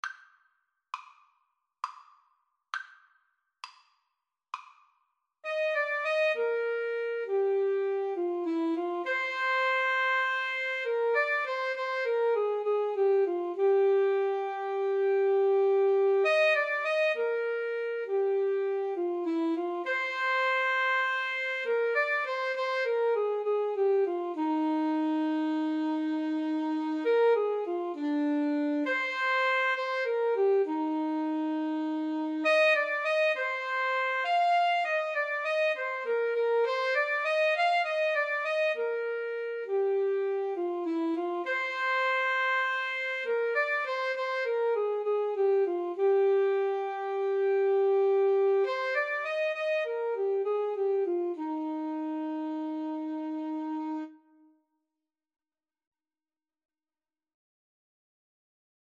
9/8 (View more 9/8 Music)
Moderato